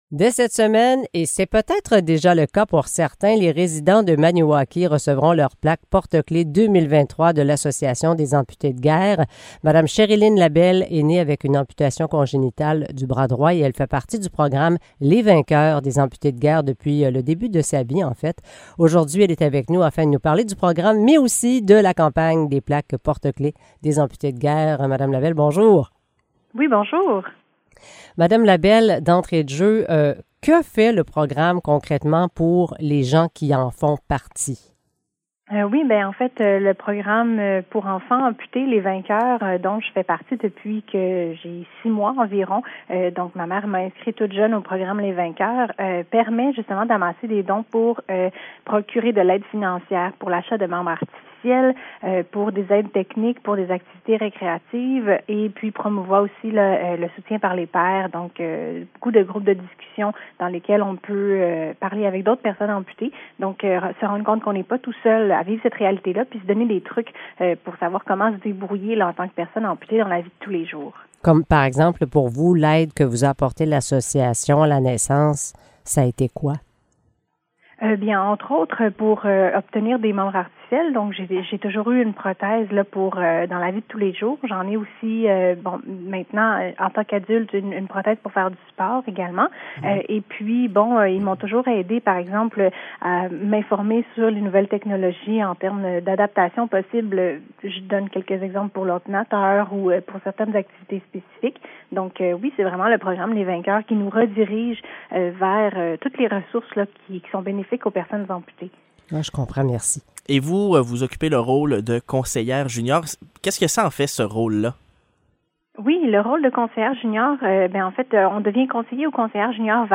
Entrevue avec une responsable du programme Les vainqueurs de l'Association des amputés de guerre